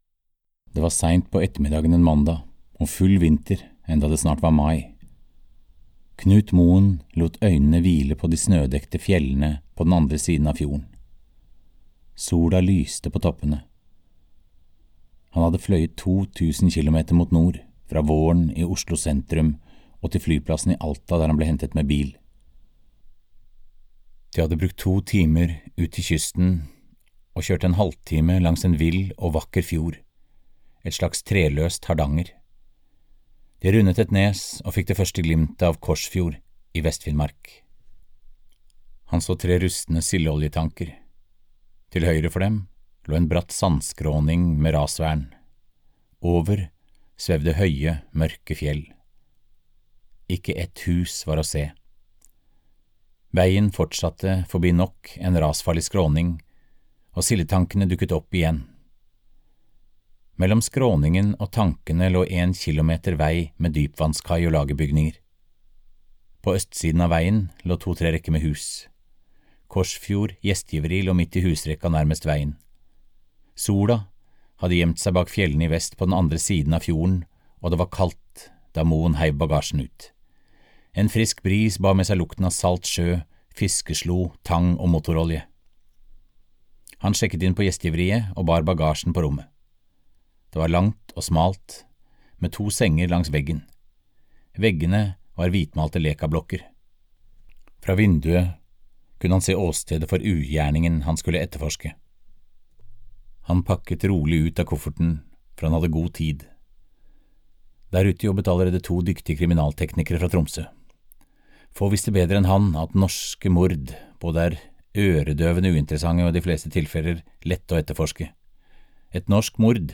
Raymond Isaksens utgang - kriminalroman (lydbok) av Jørgen Gunnerud